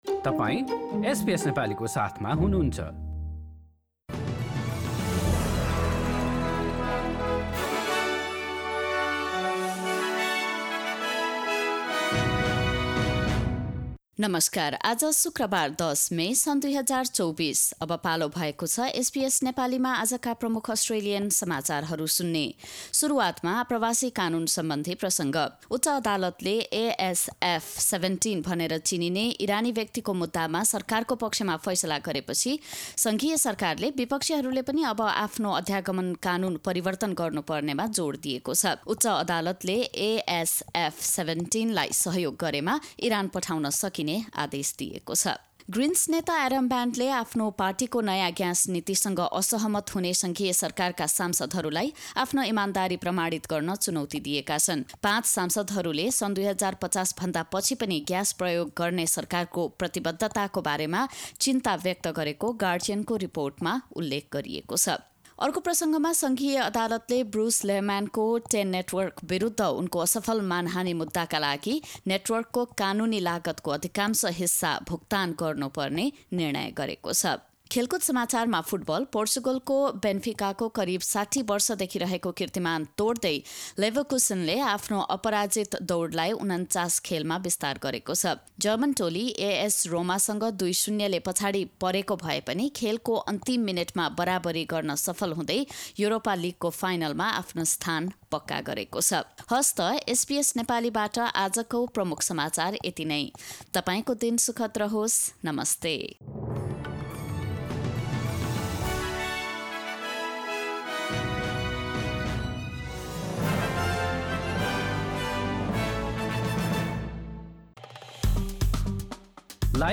एसबीएस नेपाली प्रमुख अस्ट्रेलियन समाचार: शुक्रवार, १० मे २०२४